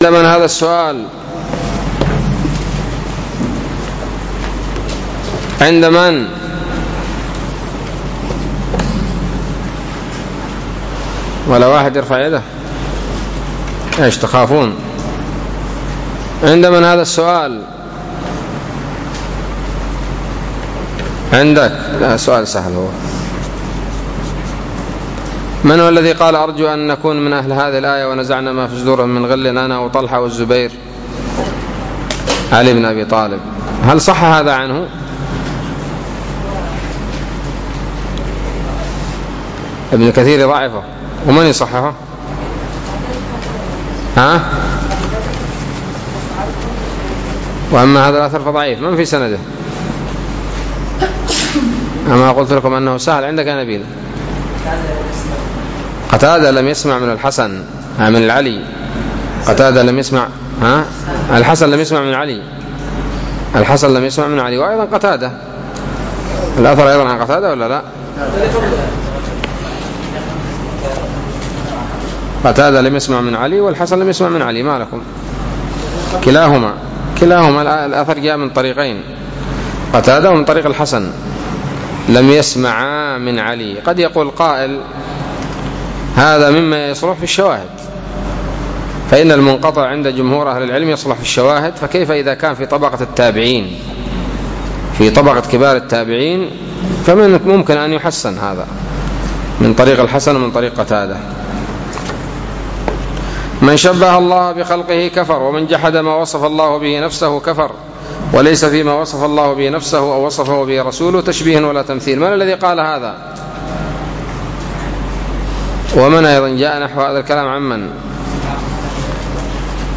الدرس الثالث والعشرون من شرح العقيدة الواسطية